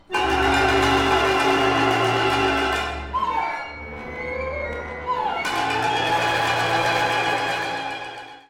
< The Crooked Horizon > for for Chamber Orchestra
UCSD⇔AUA Composers Concert
(Jun 2015, AUA chamber music hall)
Vocal Soprano